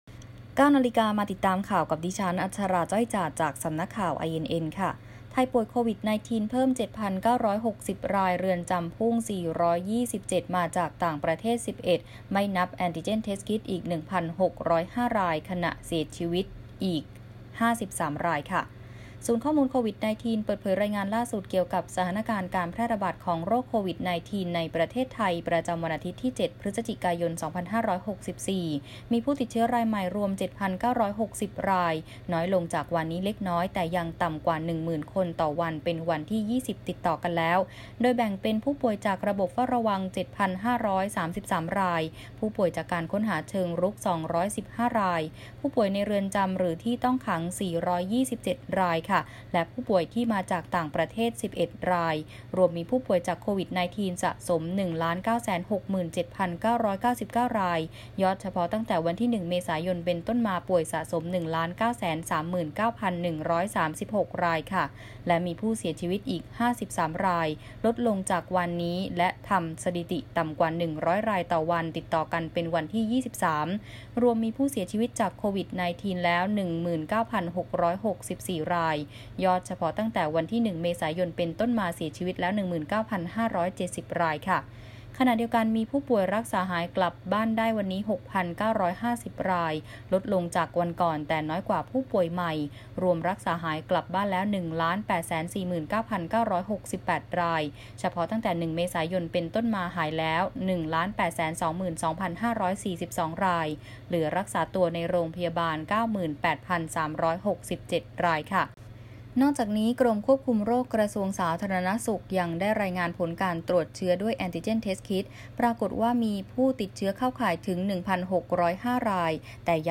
ข่าวต้นชั่วโมง 09.00 น.